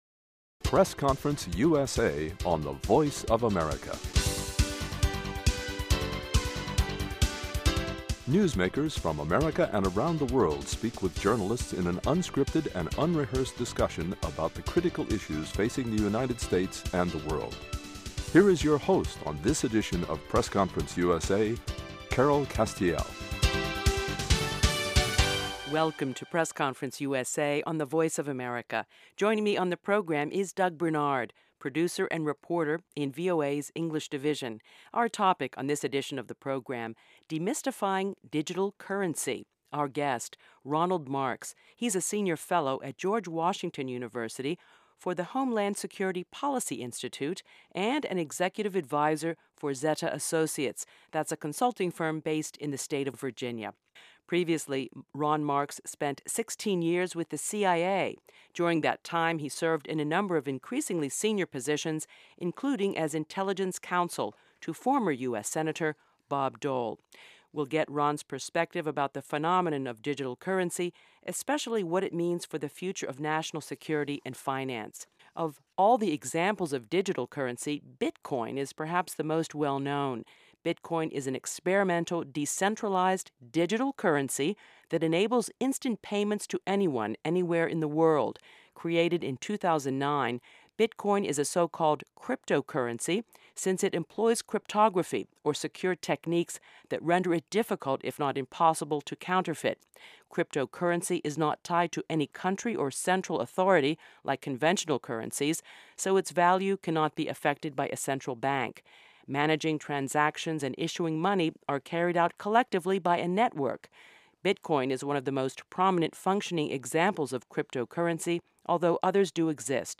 BITCOIN On this edition of the program, a conversation about the pros and cons of digital currency.